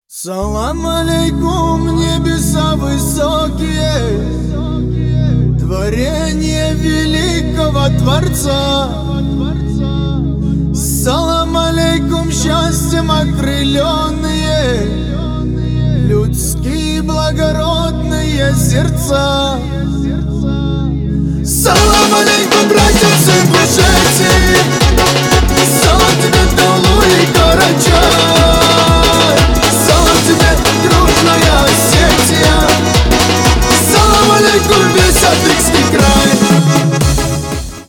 • Качество: 320, Stereo
душевные
быстрые
кавказские
лезгинка